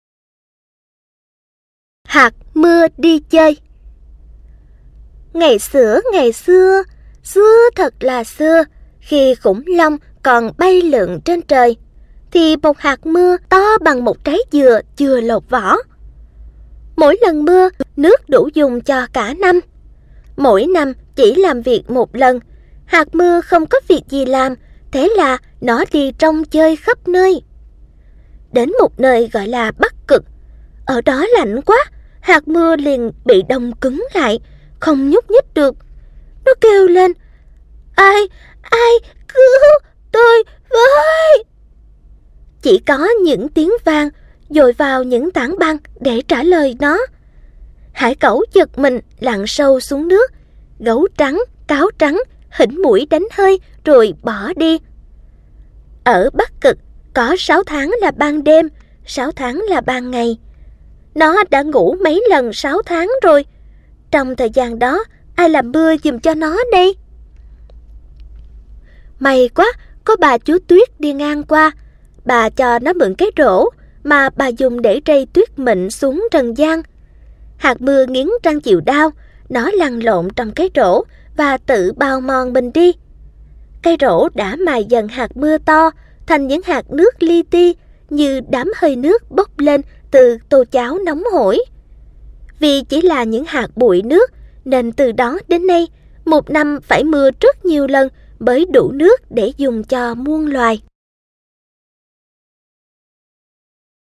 Sách nói | Xóm đồ chơi